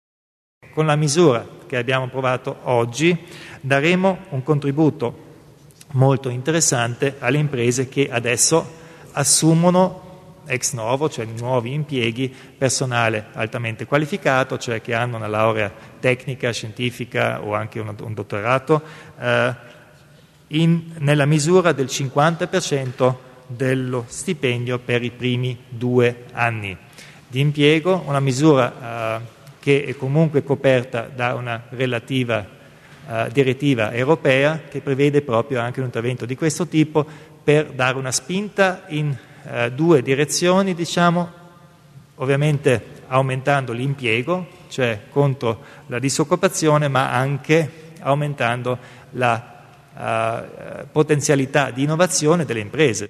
Il Presidente Kompatscher spiega le misure a favore dell'occupazione